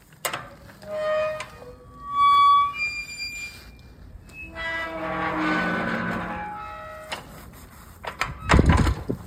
Bruit du portail